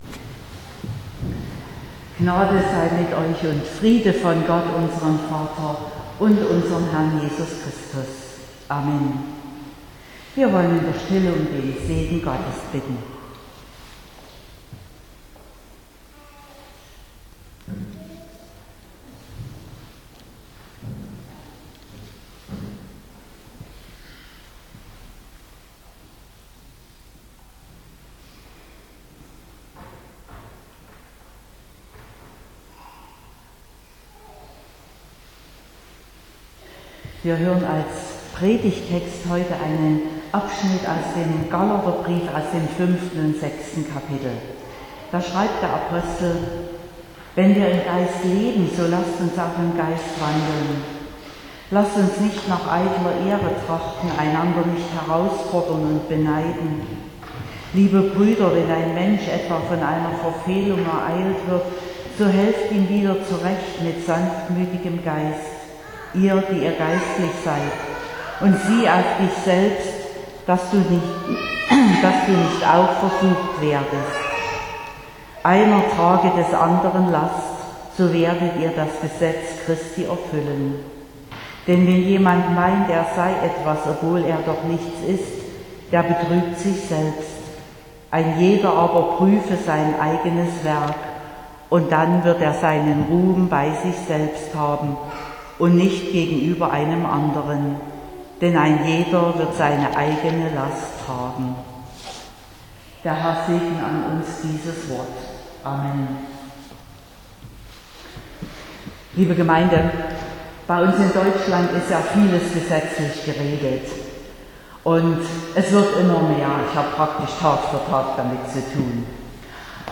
25.09.2022 – Gottesdienst
Predigt (Audio): 2022-09-25_Die_Lastenhandhabungsverordnung_Gottes.mp3 (23,5 MB)